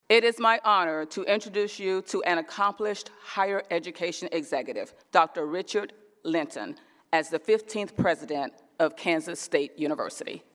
That’s Cheyrl Harrison-Lee, Chair of the Kansas Board of Regents.